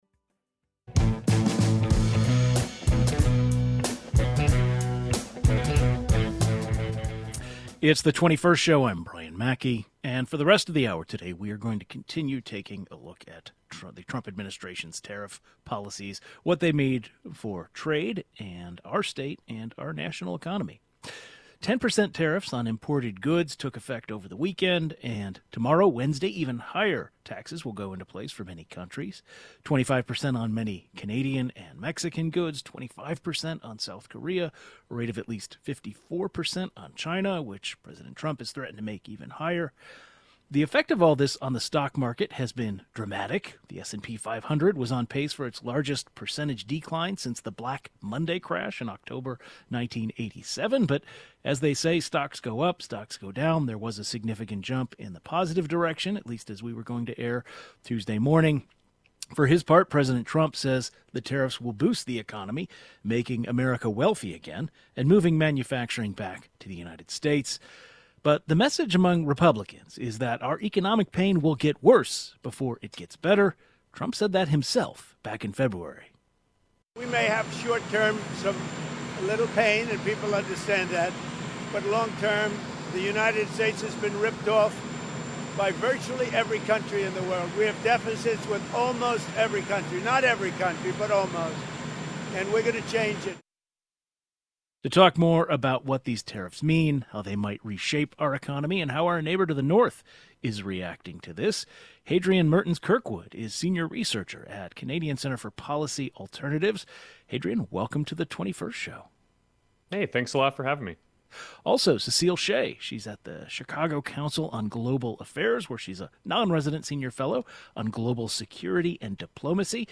A panel of experts specializing in economic policies and global affairs discuss how these tariffs might reshape the economy and how our neighbor to the north is reacting to all this.